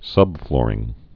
(sŭbflôrĭng) or sub·floor (-flôr)